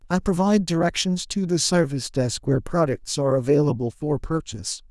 TTS_audio / PromptTTS++ /sample1 /Template2 /Condition /Customer /Emotion /angry /peeved.wav
peeved.wav